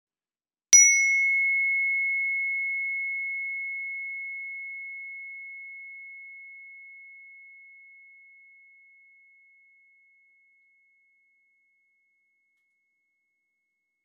Meinl Sonic Energy Energy Chime - Earth - 2177,60 Hz (EC-E)
Each chime is tuned to create the celestial body's matching frequency.
The decorative wooden base is finished with an engraved logo and tuning symbol, and each chime comes with a wooden mallet that produces a clear and defined note to easily fill your space (small or large) with soothing sound.